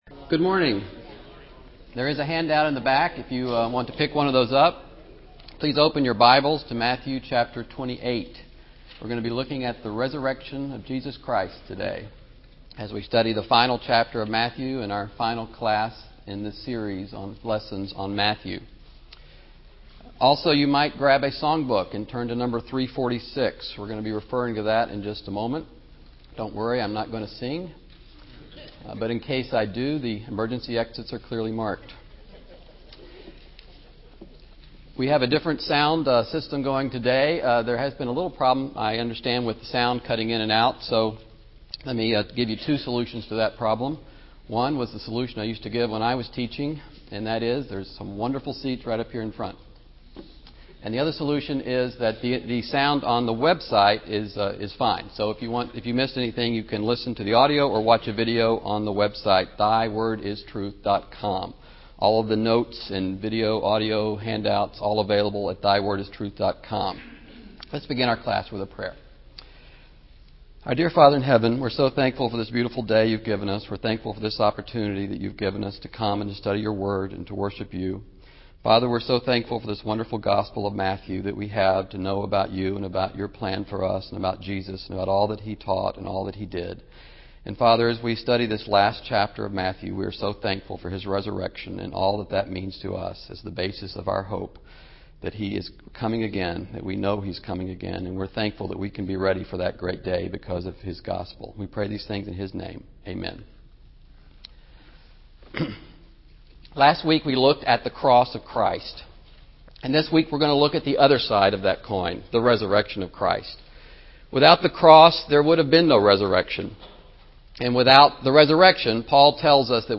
Selected Lessons on Matthew
Matthew-Lesson-04.mp3